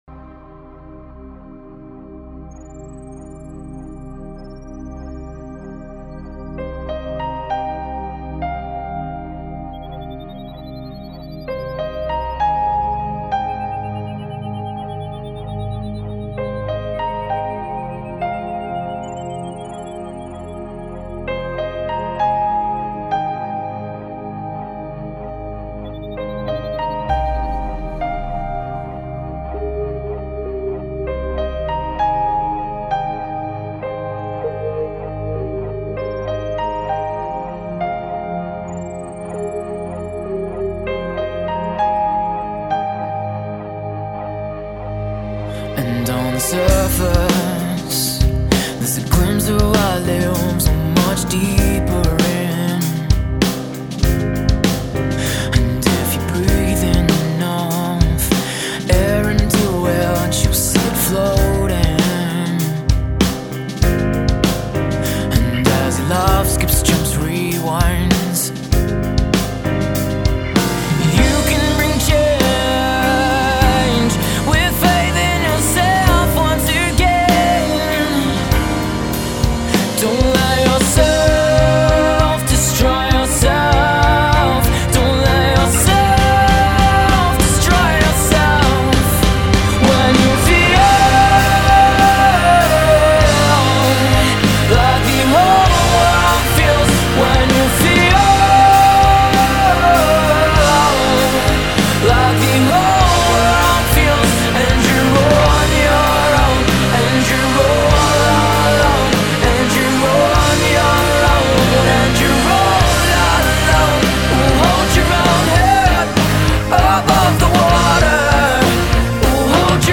- ژانر راک آلترناتیو